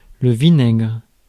Ääntäminen
Synonyymit aigre Ääntäminen France: IPA: /vi.nɛɡʁ/ Haettu sana löytyi näillä lähdekielillä: ranska Käännös Substantiivit 1. vinagre {m} Suku: m .